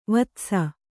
♪ vatsa